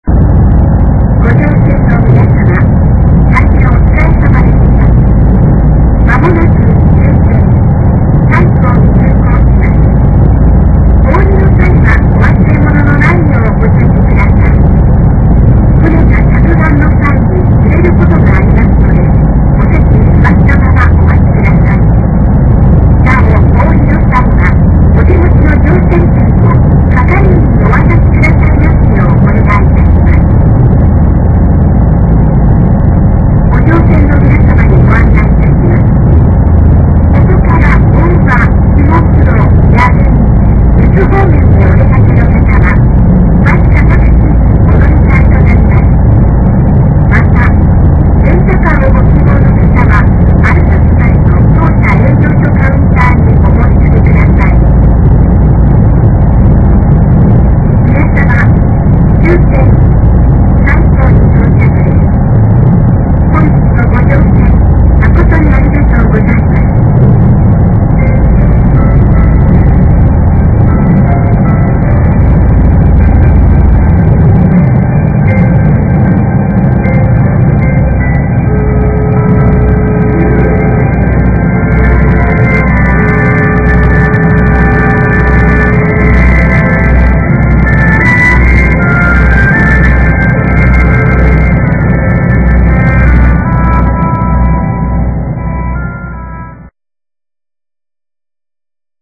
そして終点の佐井には定刻１０分前に到着した。
到着放送の最後には音楽がかかり俺が下船した後もなりつづけていた。